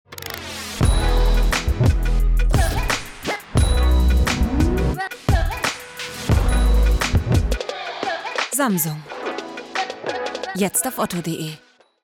hell, fein, zart, sehr variabel, dunkel, sonor, souverän
Mittel minus (25-45)
Commercial (Werbung)